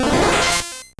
Cri de Stari dans Pokémon Or et Argent.